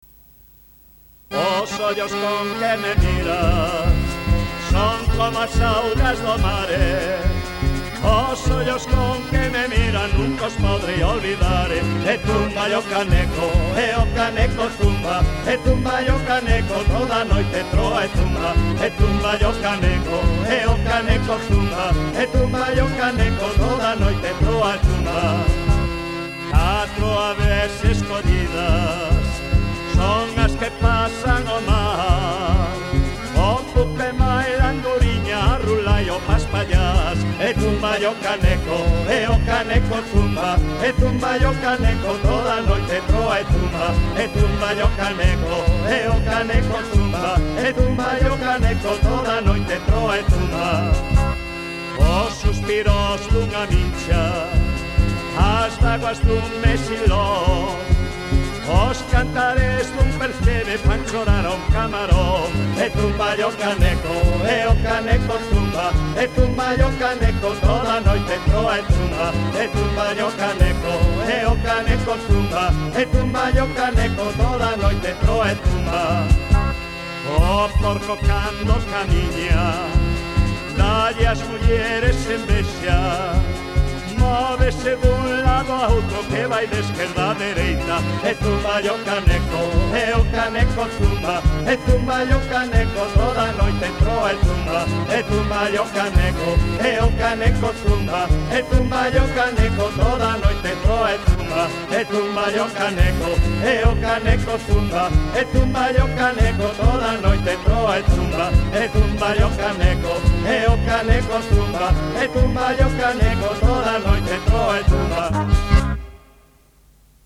Letra: Popular
Música:Popular